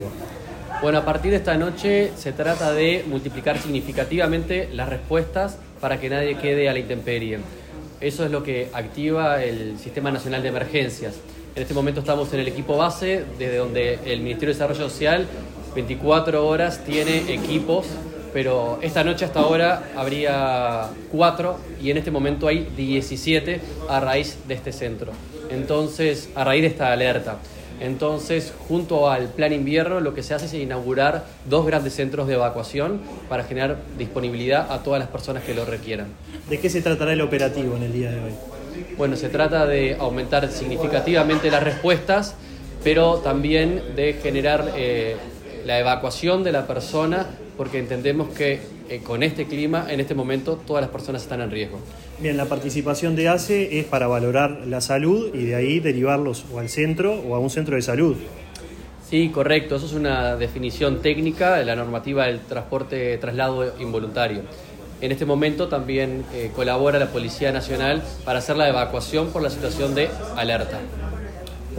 Declaraciones del director nacional de Protección Social, Daniel Gerhard
Declaraciones del director nacional de Protección Social, Daniel Gerhard 23/06/2025 Compartir Facebook X Copiar enlace WhatsApp LinkedIn El director nacional de Protección Social, Daniel Gerhard, dialogó con la prensa en el comienzo del operativo de evacuación de personas en situación calle.